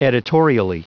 Prononciation du mot editorially en anglais (fichier audio)
Prononciation du mot : editorially